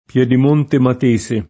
pLHdim1nte mat%Se], fino al 1970 Piedimonte d’Alife [